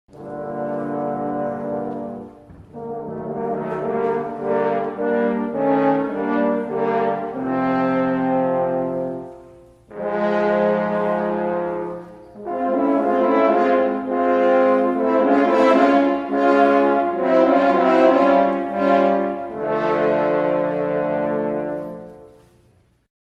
Hoorn
Om meer precies te zijn, eigenlijk ben ik de Franse hoorn. Ik maak deel uit van de koperblazers.